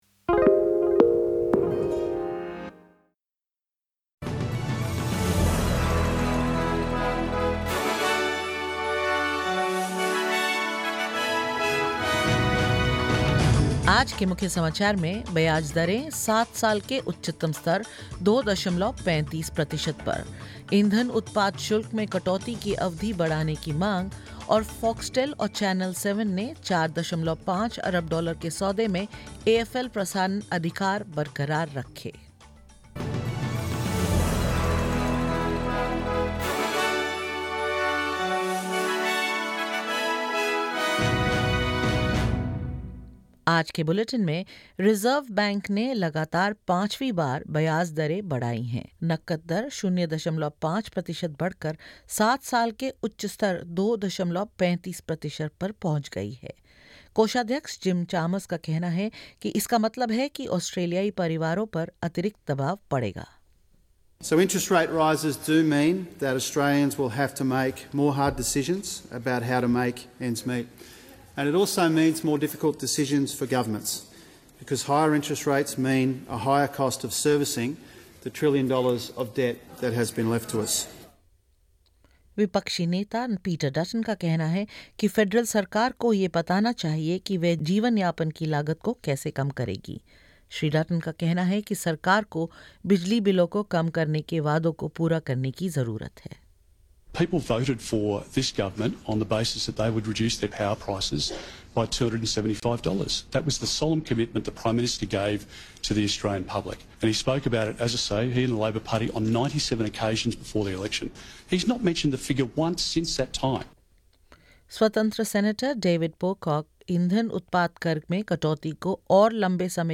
SBS Hindi News 06 September 2022: Treasurer Jim Chalmers predicts more pressure on Australians as interest rates hike